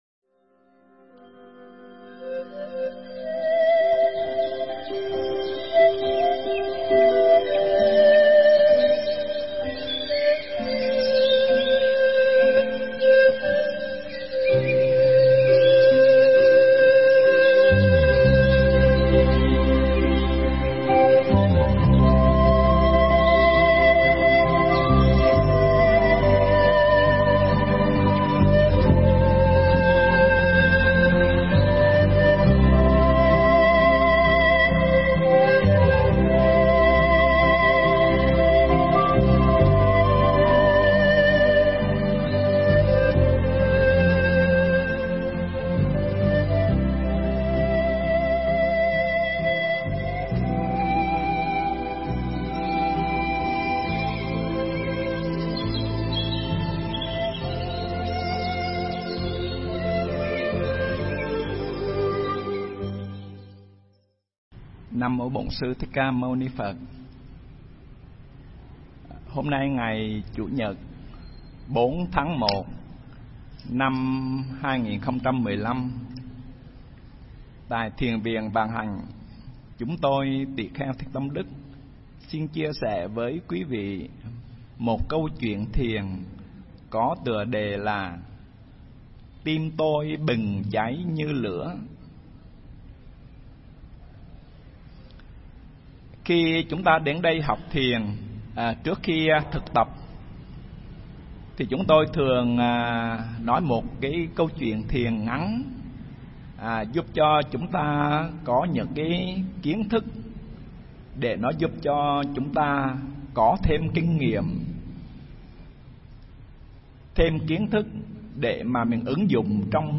Nghe Mp3 thuyết pháp Tim Tôi Bừng Cháy Như Lửa